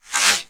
SCIFI_Sweep_07_mono.wav